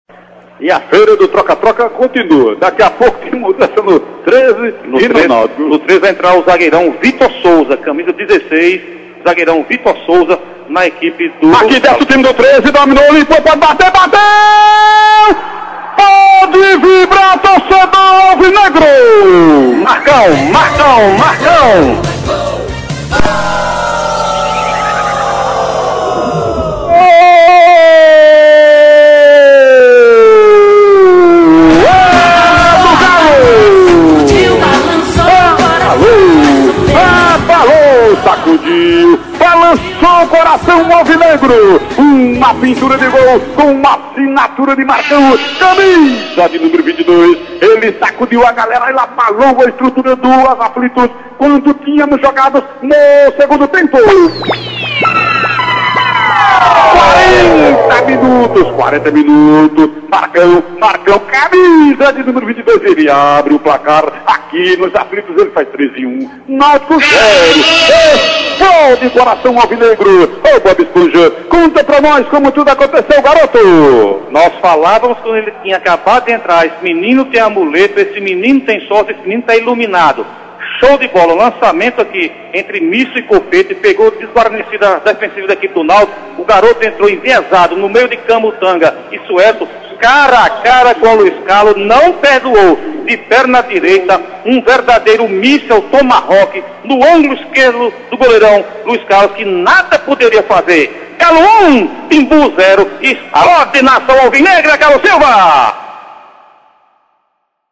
Treze 1×0 Nautico-PE, nos Aflitos, em Recife.